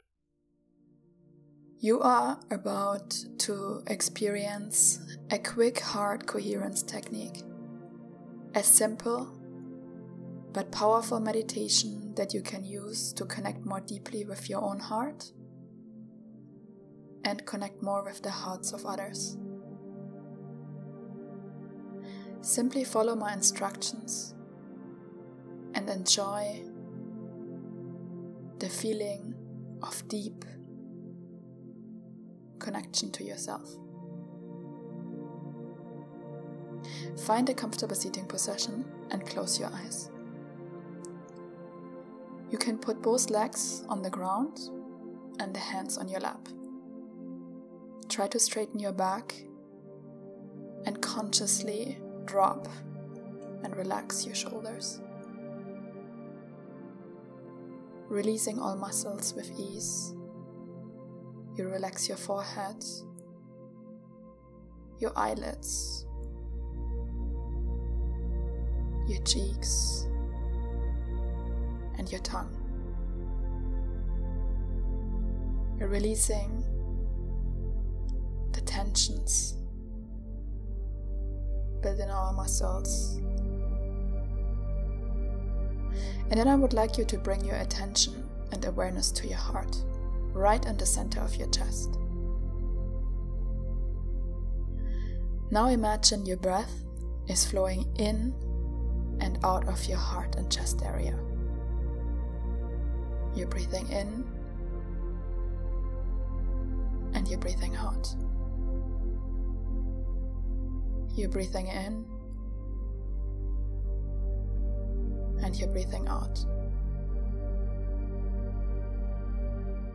Change your frequency fast and uplevel your energy with this free guided practice which is rooted in the understanding that our beautiful heart generates a powerful electromagnetic field which will influence your overall wellbeing.